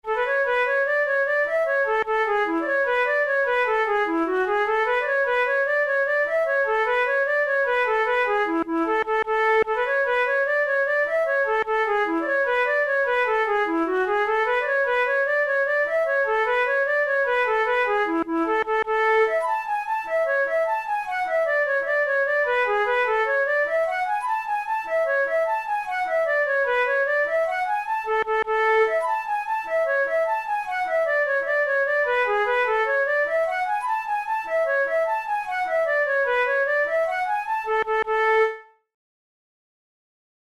InstrumentationFlute solo
KeyA major
Time signature6/8
Tempo100 BPM
Jigs, Traditional/Folk
Traditional Irish jig